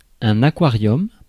Ääntäminen
Ääntäminen : IPA: /a.kwa.ʁjɔm/ Haettu sana löytyi näillä lähdekielillä: ranska Käännös Konteksti Ääninäyte Substantiivit 1. tank kala 2. aquarium US 3. bowl US Suku: m .